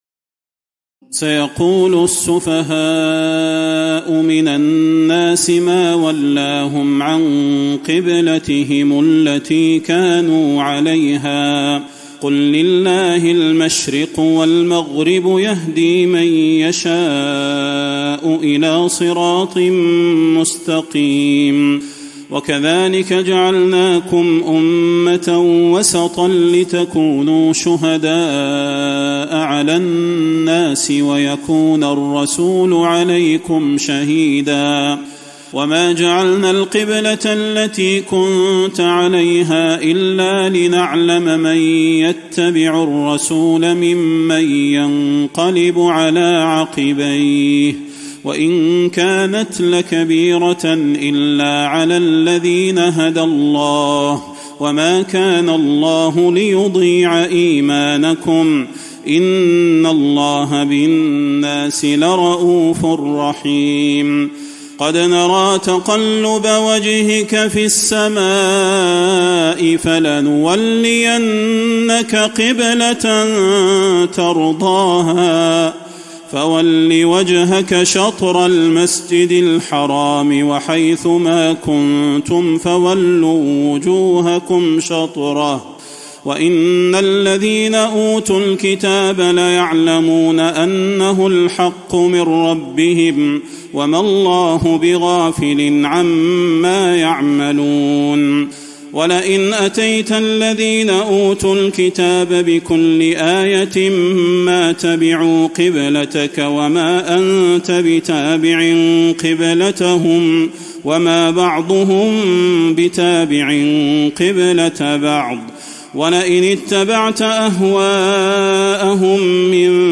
تراويح الليلة الثانية رمضان 1435هـ من سورة البقرة (142-212) Taraweeh 2 st night Ramadan 1435H from Surah Al-Baqara > تراويح الحرم النبوي عام 1435 🕌 > التراويح - تلاوات الحرمين